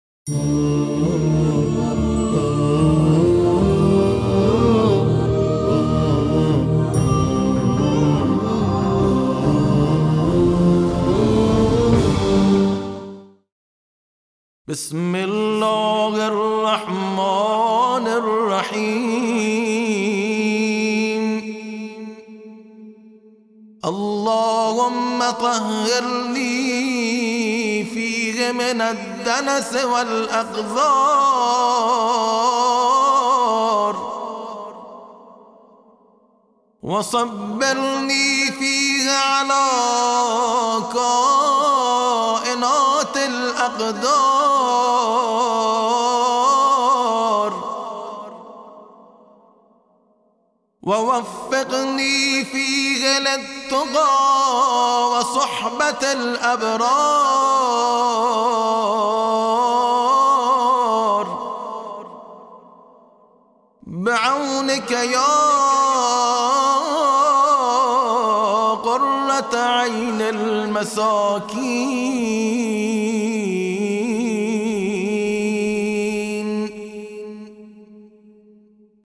دعای روز سیزدهم ماه رمضان صوتی